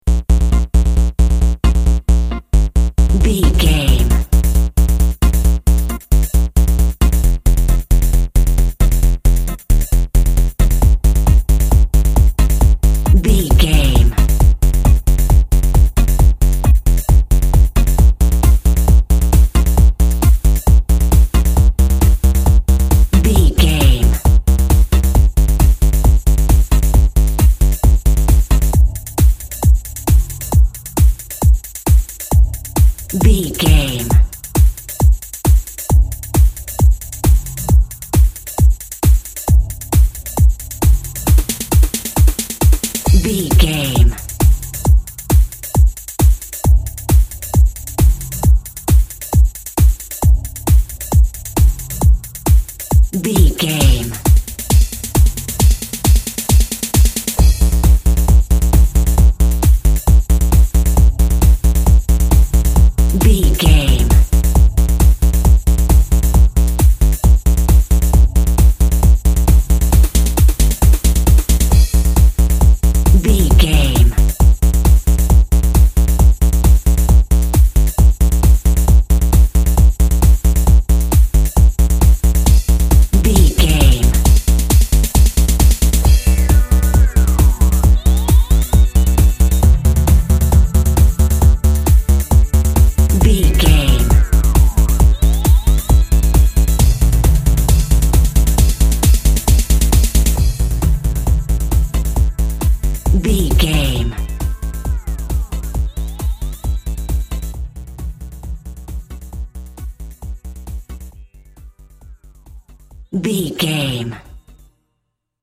Electro House Music Cue.
Fast paced
Aeolian/Minor
B♭
aggressive
dark
epic
synthesiser
drum machine
techno
glitch
synth lead
synth bass
Synth Pads